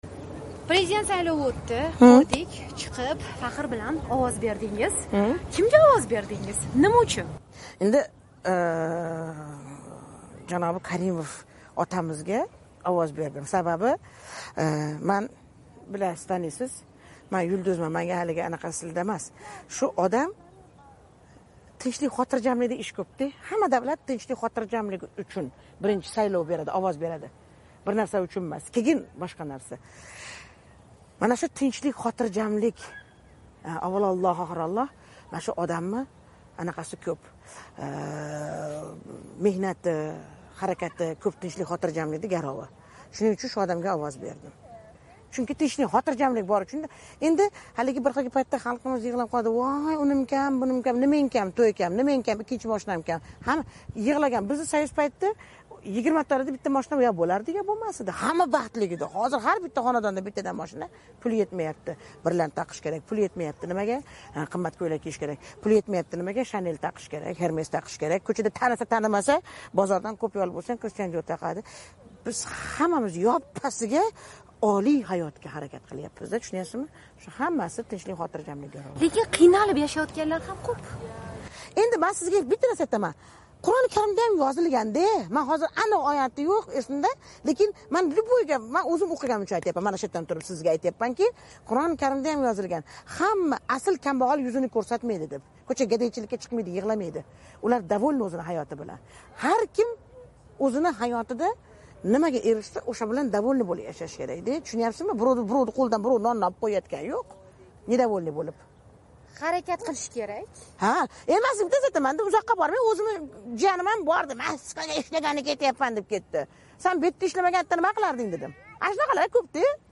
O'zbekiston xalq artisti Yulduz Usmonova "Amerika Ovozi" bilan suhbatda Andijon fojiasi yuzasidan ham o'z fikrlarini bildirdi.